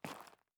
脚步
02_室外_2.wav